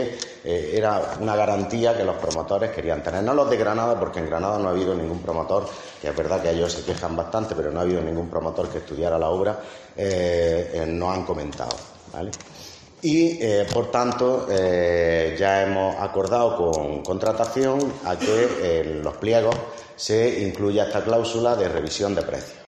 Miguel Angel Fernández Madrid, concejal de urbanismo